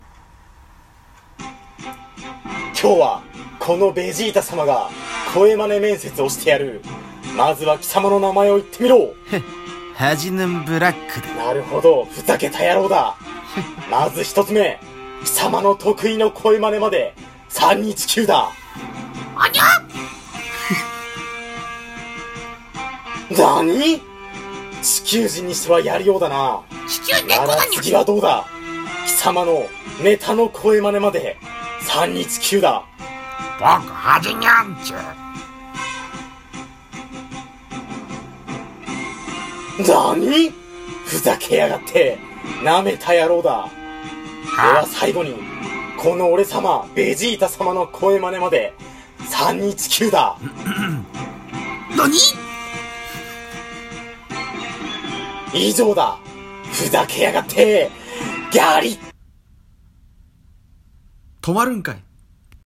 ベジータ様の声真似面接